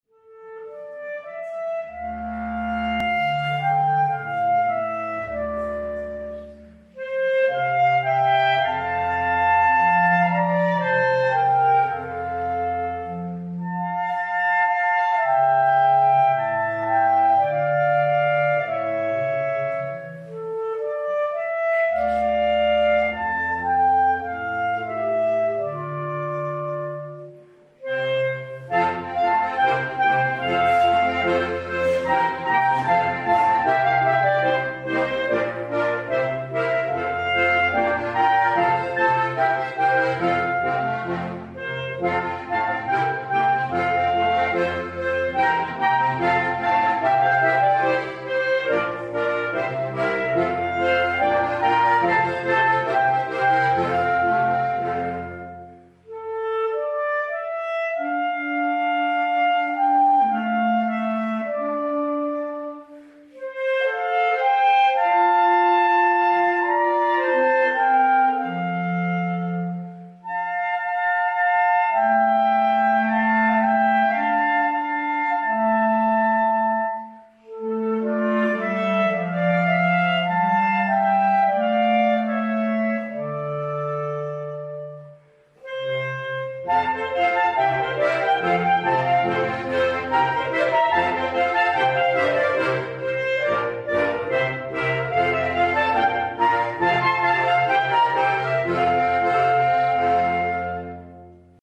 Messe auf Schloss Röthelstein
Musikalisch wurden im Quartett Teile aus der Katschtaler- und der Schubertmesse gespielt.
das Klarinettenquartett
in der Benediktuskapelle auf Schloss Röthelstein (Admont)